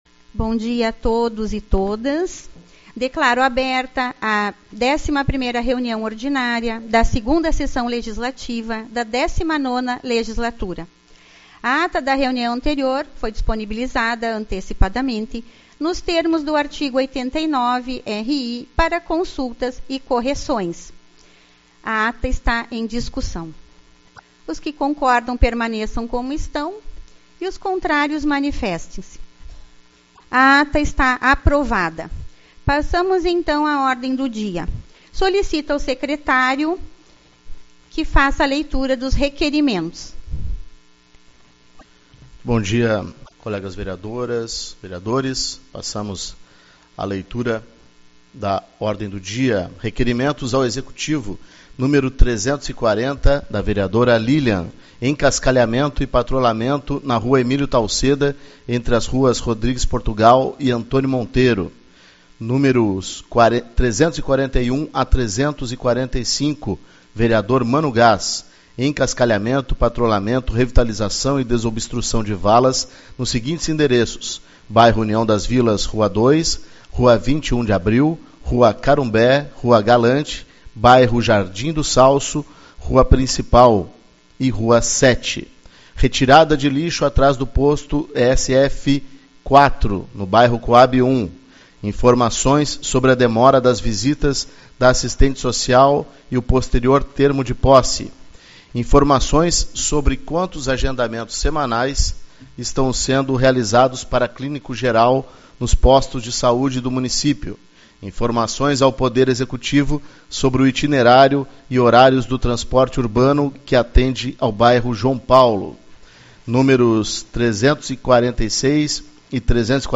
Reunião Ordinária